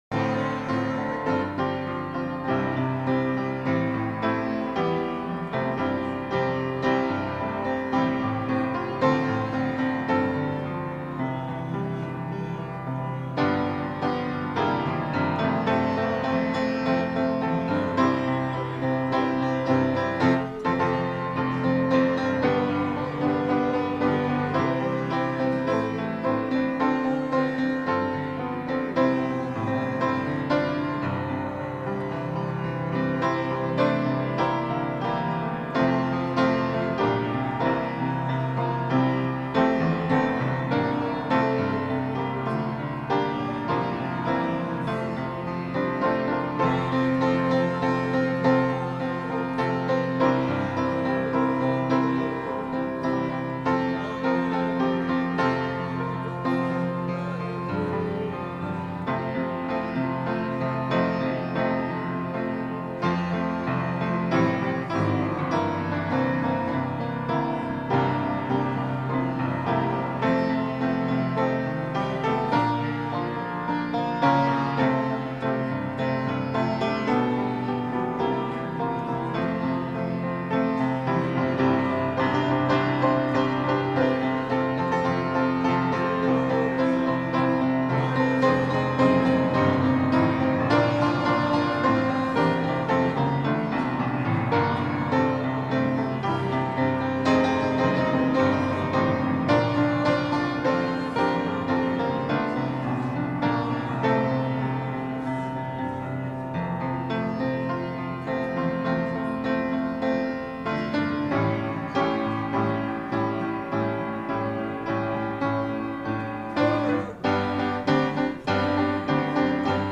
Worship-July-7-2024-Voice-Only.mp3